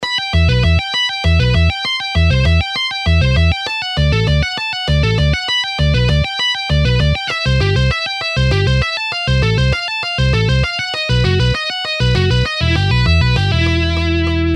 Guitar Sweeping Exercises – 4
Sweep-Picking-Exercise-4-1.mp3